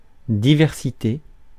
Ääntäminen
Synonyymit pluralisme Ääntäminen France: IPA: [di.vɛʁ.si.te] Haettu sana löytyi näillä lähdekielillä: ranska Käännös Ääninäyte Substantiivit 1. diversity UK Suku: f .